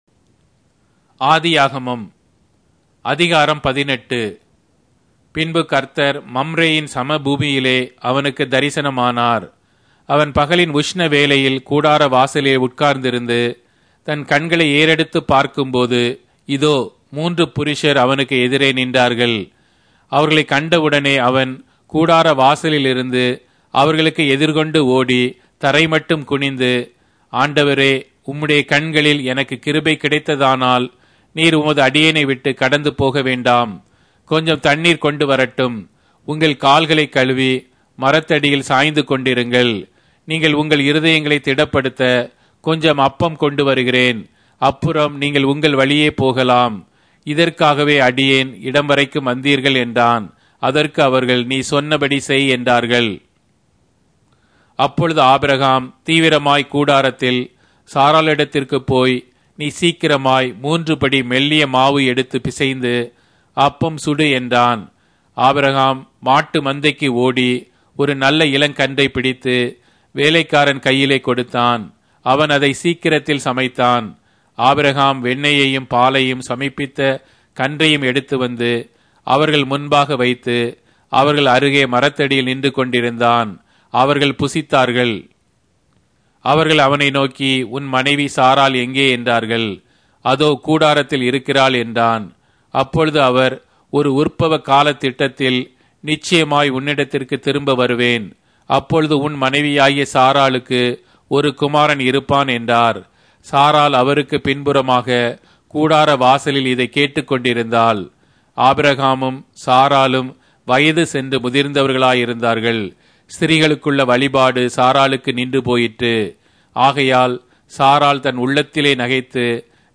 Tamil Audio Bible - Genesis 21 in Mkjv bible version